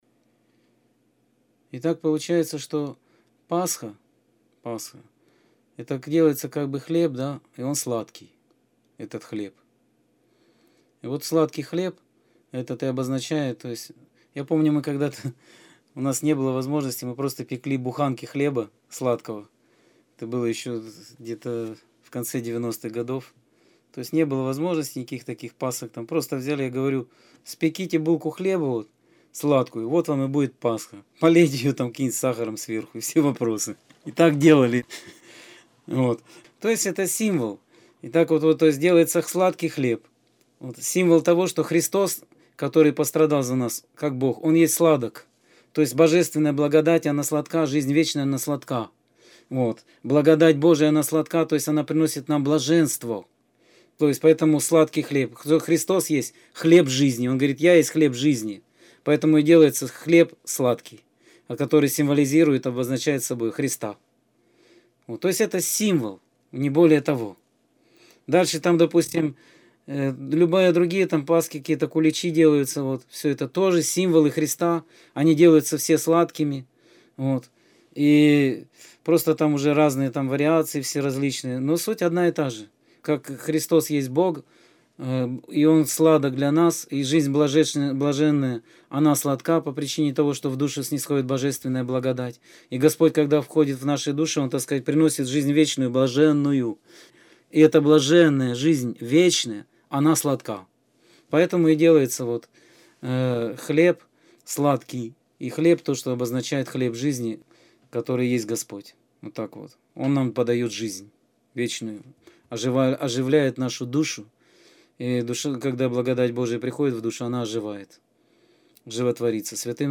Скайп-беседа 19.04.2014